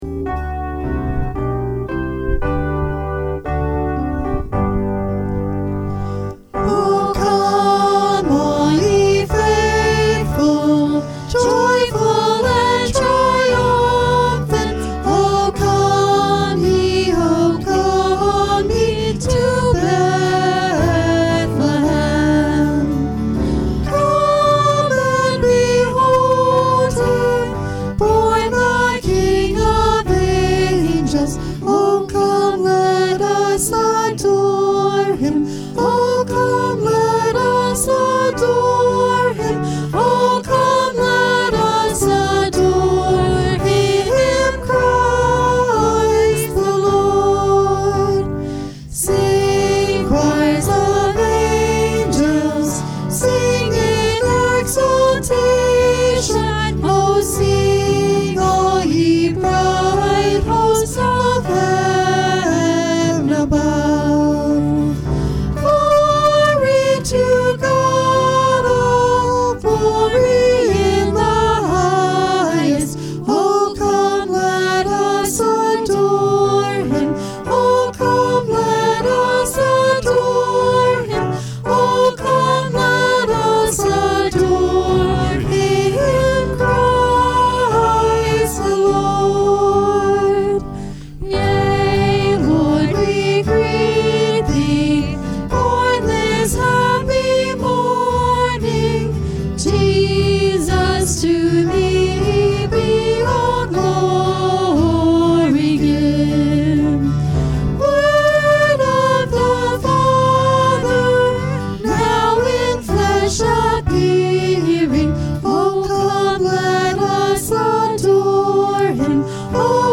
Christmas Eve Service 12/24/2020